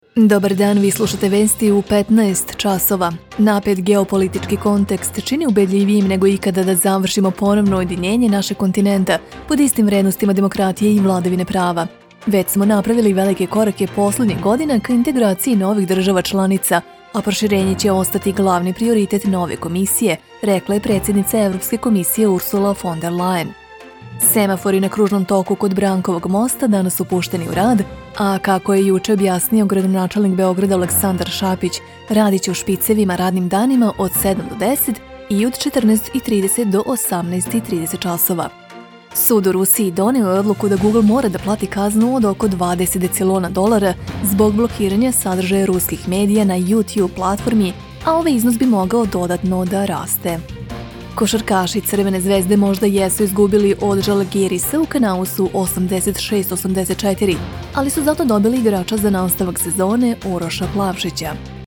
Announcements
Looking for the right neutral female Serbian voice-over artist – accent-free – for your project?
Vocal booth